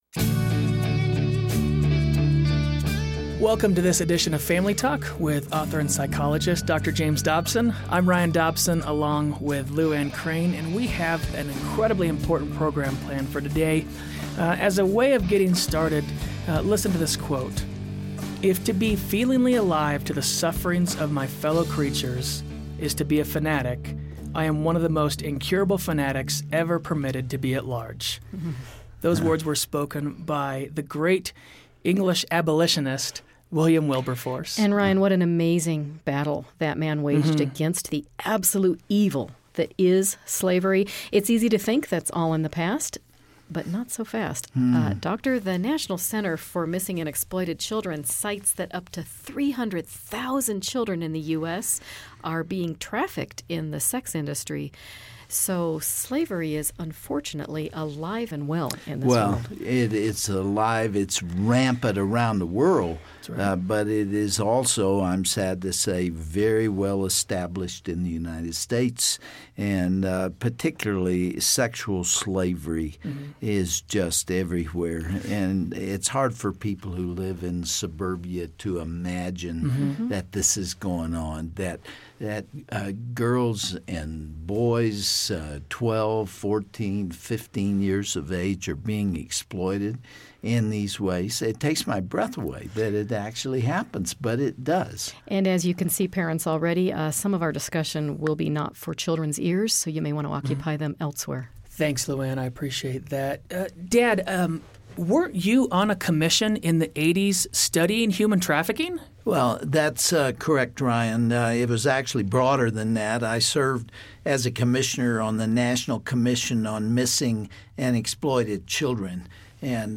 Find out today as Dr. Dobson in joined in the studio by two young women who are determined to expose and eradicate this evil.